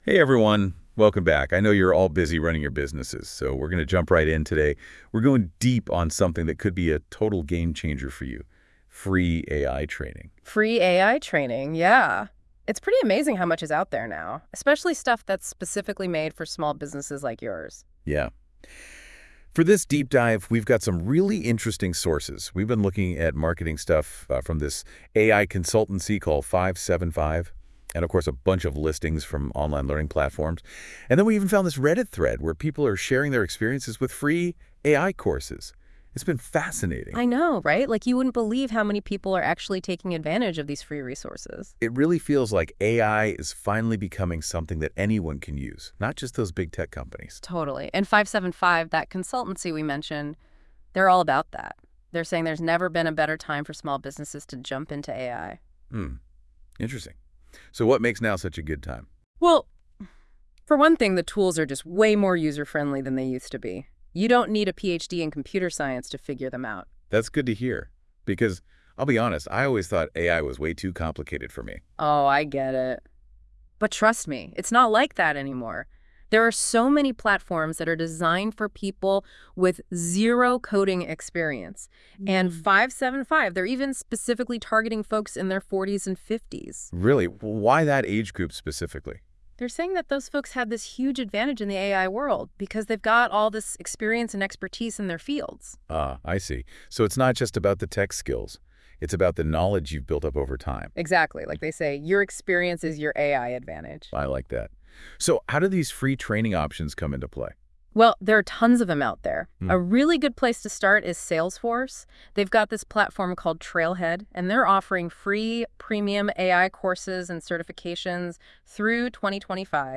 In this Deep DIVE: Your friendly FIVE75 AI hosts talk about free AI courses that can give you certifications that you can add to your resume and LinkedIn AND the tools learned will help your small business thrive.